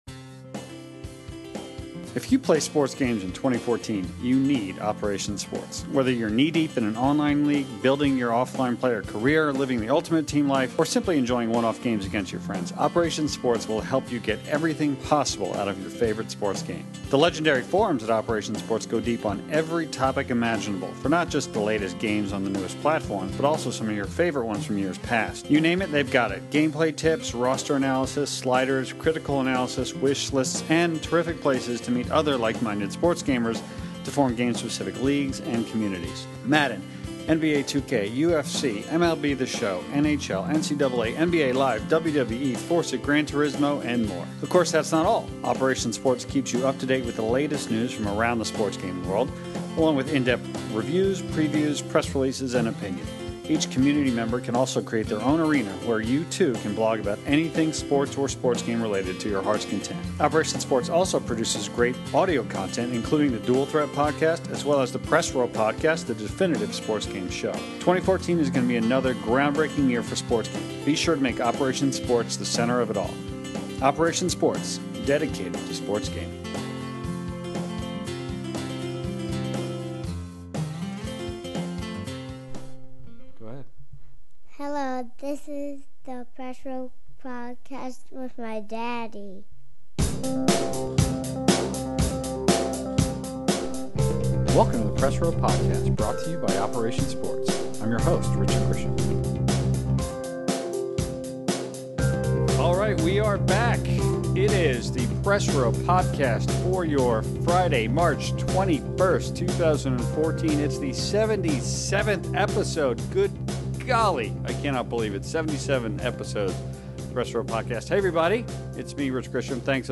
In the 77th episode of the Press Row Podcast, the largest panel to date gathers to discuss baseball “outside the box” as we near the start of the real and virtual hardball seasons.
The entire episode is focused on different ways the panelists would like to experience baseball – from historical options to new online features to immersive technology. It’s a very different episode than we’ve ever done; each panelist is given the opportunity to present their idea and have the rest of the crew react (positively or not).